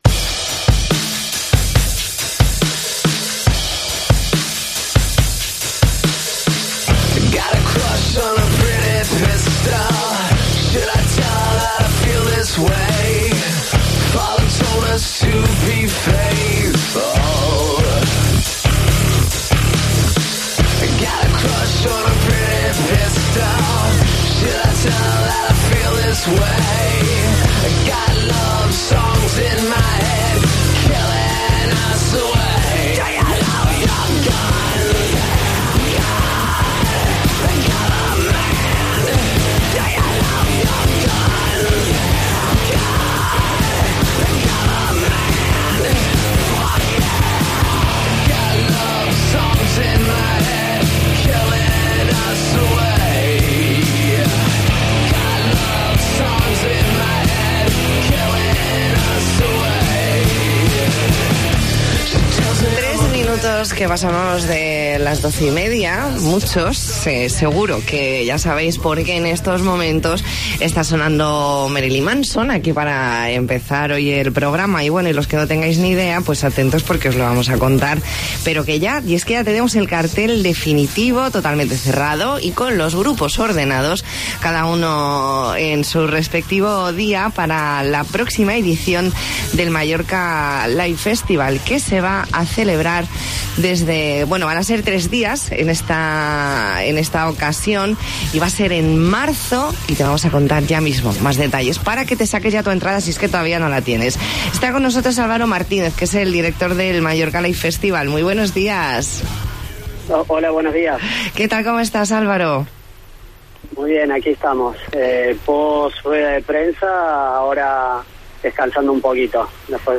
Entrevista en La Mañana en COPE Más Mallorca, miércoles 4 de marzo de 2020.